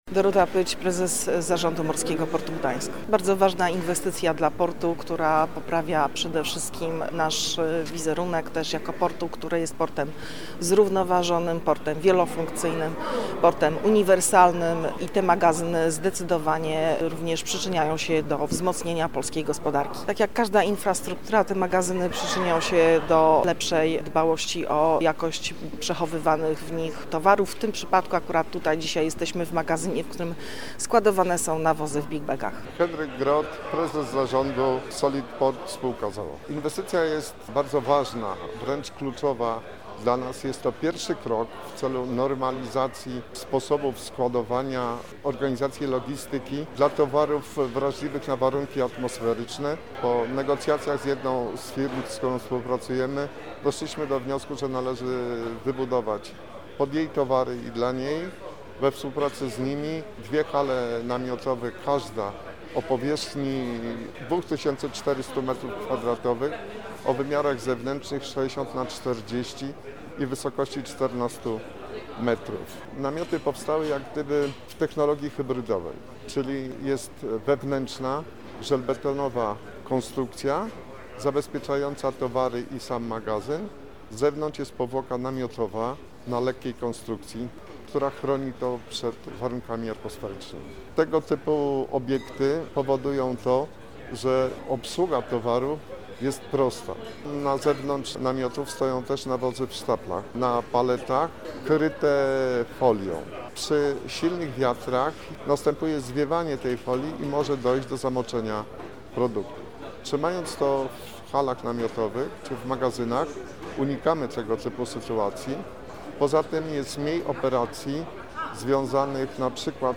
Jak mówili zgromadzeni na uroczystym otwarciu, inwestycja zwiększy efektywność i potencjał operacyjny Portu Gdańsk.